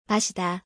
発音
마시다マシダmasida
korean-drink-masida.mp3